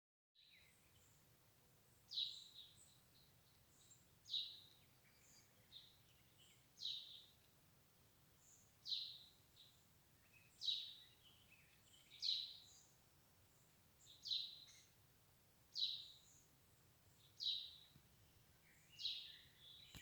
Chaffinch, Fringilla coelebs
StatusRecently fledged young (nidicolous species) or downy young (nidifugous species)